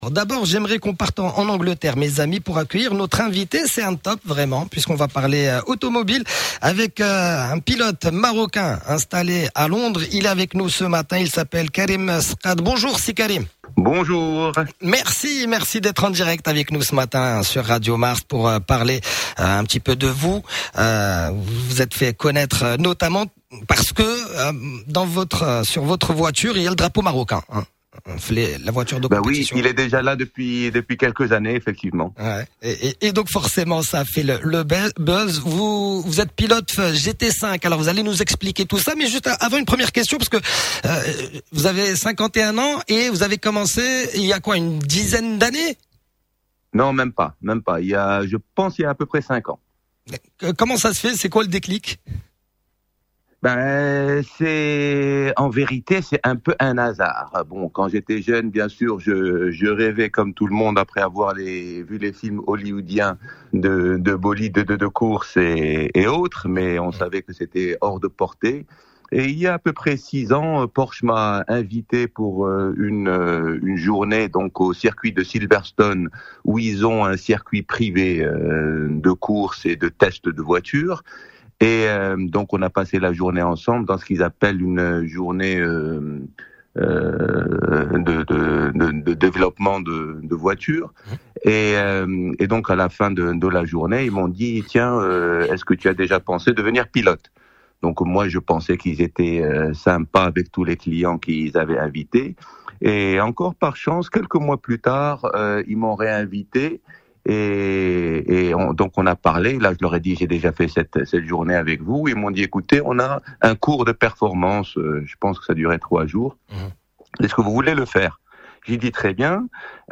Le Buzz avec Radio Mars Attack interview